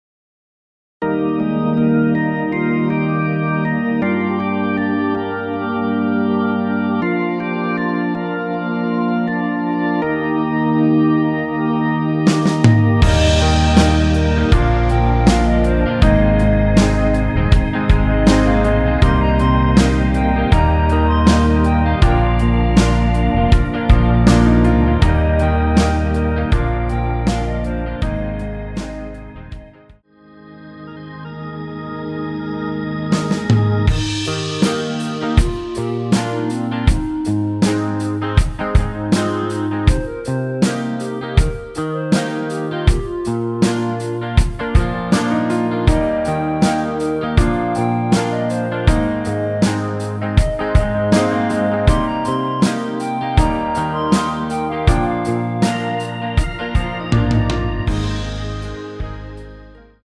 Eb
멜로디 MR이란
멜로디 MR이라고 합니다.
앞부분30초, 뒷부분30초씩 편집해서 올려 드리고 있습니다.
중간에 음이 끈어지고 다시 나오는 이유는
위처럼 미리듣기를 만들어서 그렇습니다.